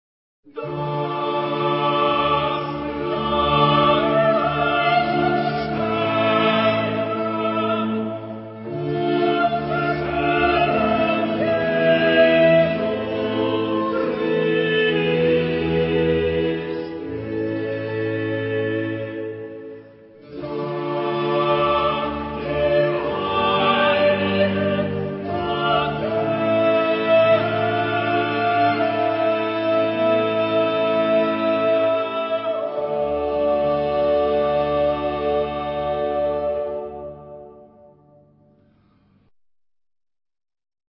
Genre-Style-Forme : Passion ; Oratorio ; Sacré
Caractère de la pièce : déclamatoire ; émouvant ; descriptif ; recueilli ; dramatique
Type de choeur : SATB  (4 voix mixtes )
Solistes : Tenor (1) OU Bass (1)  (2 soliste(s))
Instruments : Viole de gambe (3) ; Basse continue
Tonalité : ré modal ; ré mineur